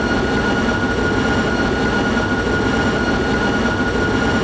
Add wing aft sounds
v2500-lowspool.wav